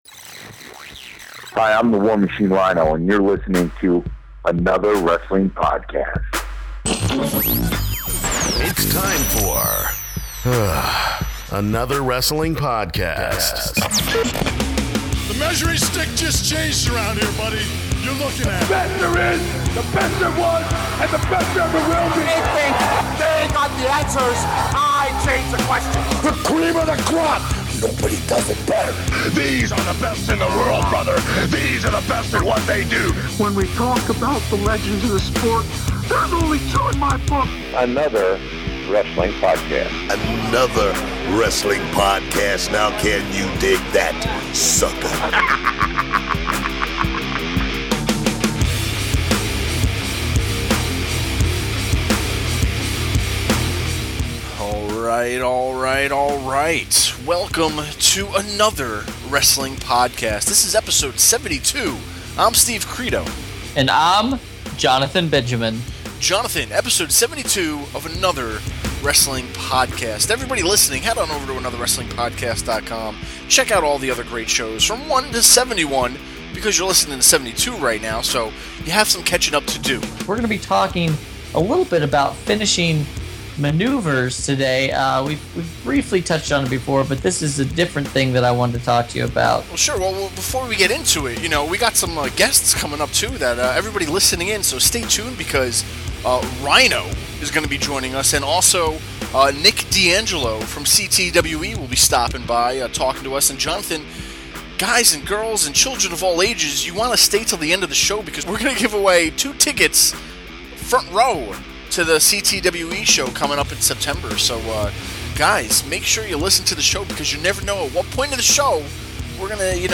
In this episode the guys are discussing how finishing moves have evolved over time. Are today's finishers over used or a tribute to the past? Joining them is former ECW alumni and current NXT superstar Rhyno!